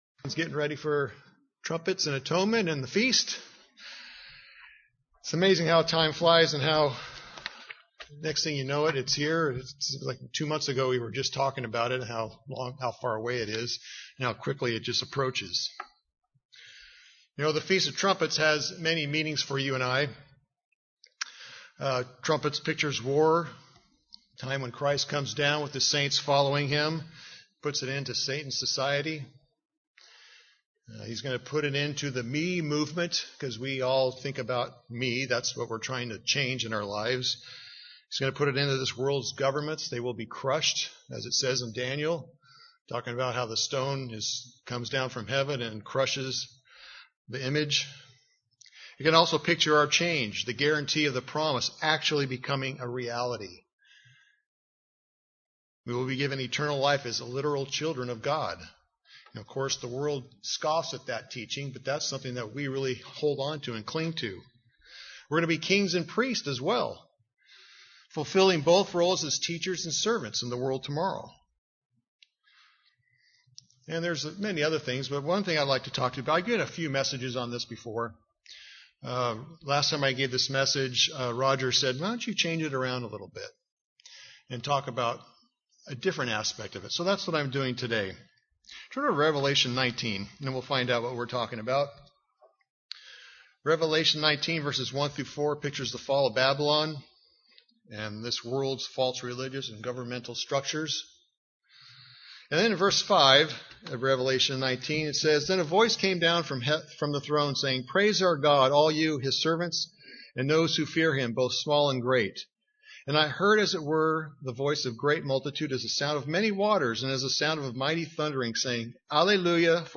Given in Burlington, WA